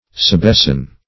Search Result for " sebesten" : The Collaborative International Dictionary of English v.0.48: Sebesten \Se*bes"ten\ (s[-e]*b[e^]s"t[e^]n), n. [Ar. sebest[=a]n the tree: cf. Sp. sebesten.]